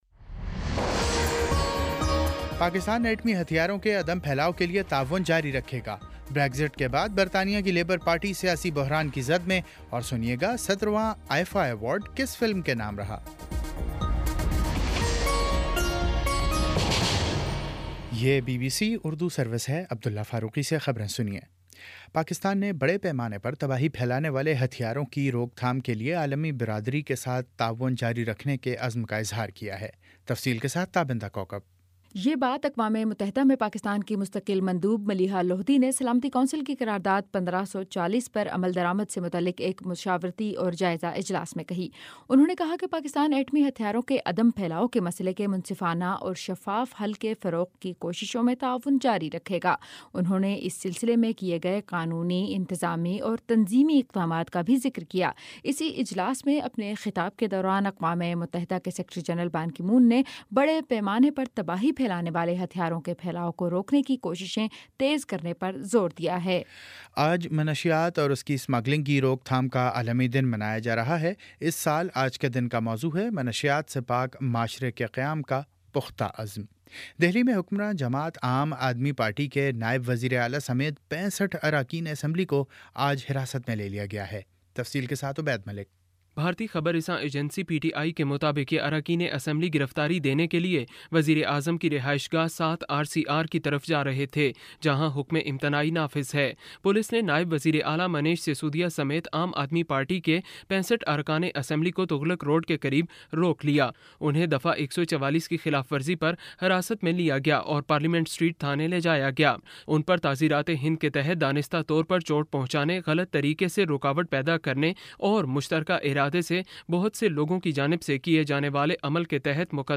جون 26 : شام پانچ بجے کا نیوز بُلیٹن